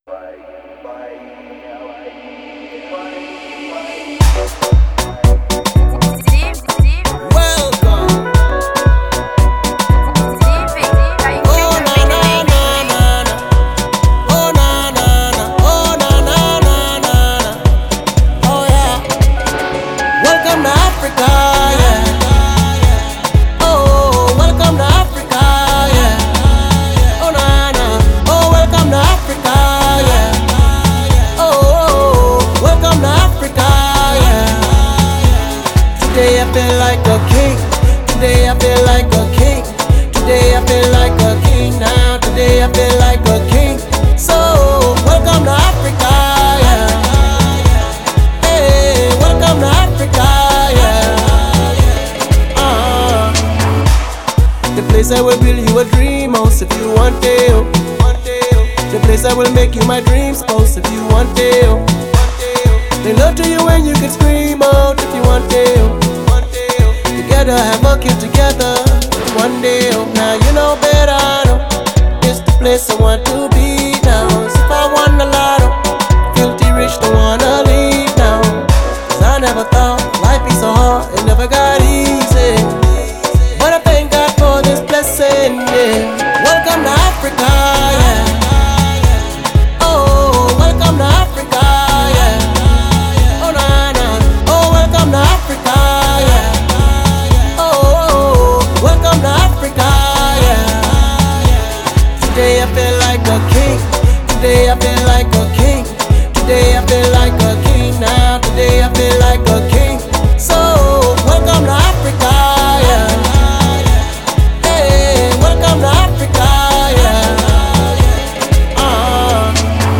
это яркая и жизнеутверждающая песня в жанре афропоп